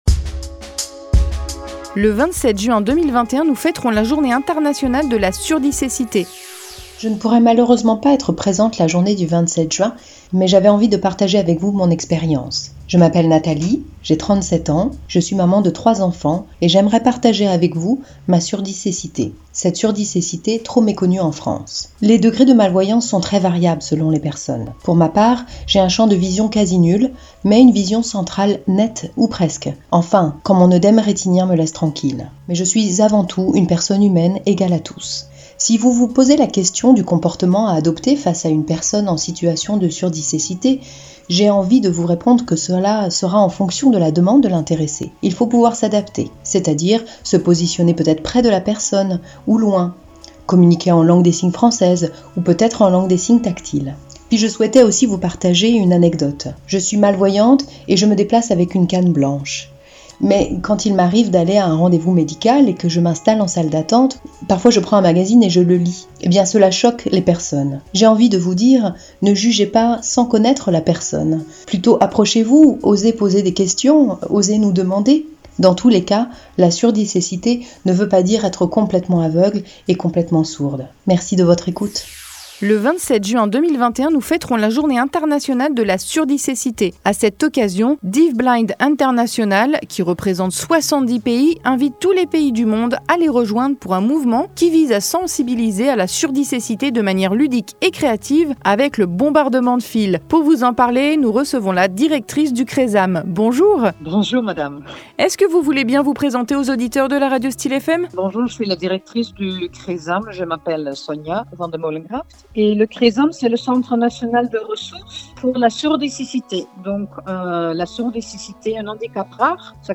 Les témoignages audio diffusés sur Styl’FM :